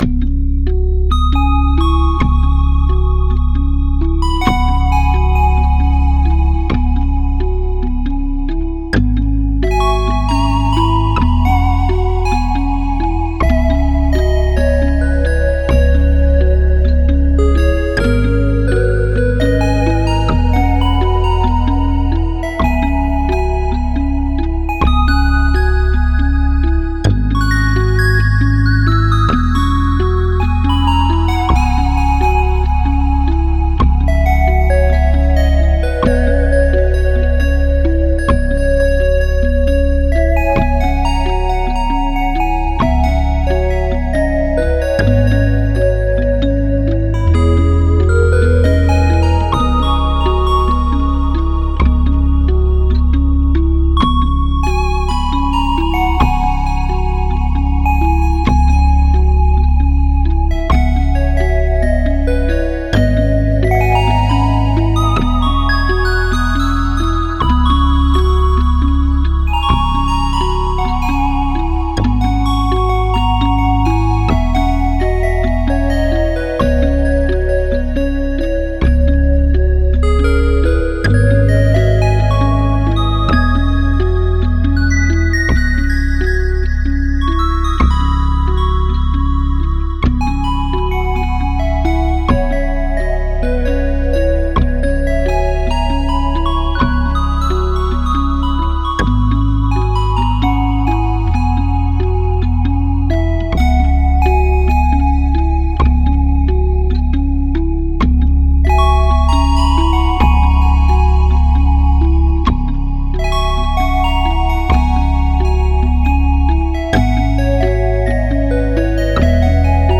bluesy_baritone_0.mp3